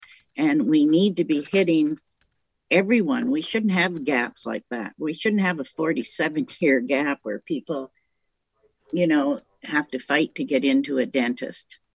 Chair Jo-Anne Albert told a meeting of the board on Tuesday, “You’re teaching them up to when they’re 17 they’re getting the resources needed.